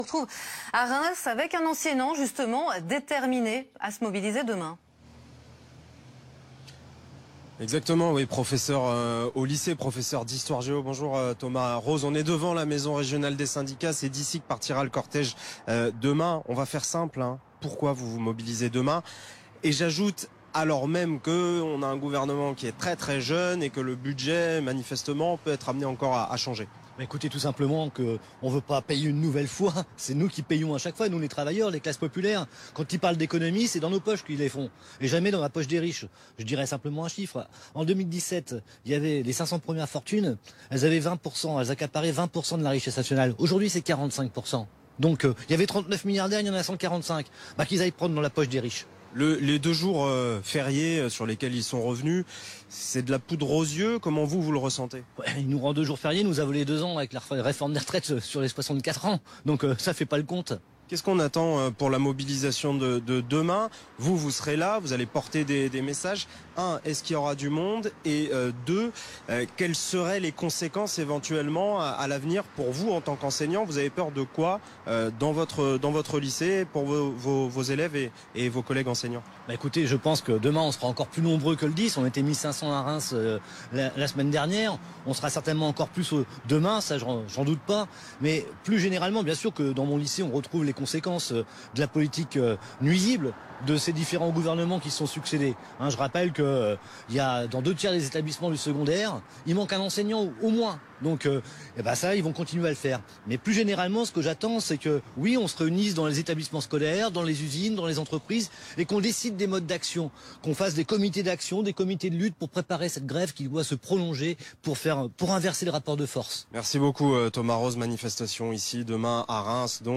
BFMTV : Interview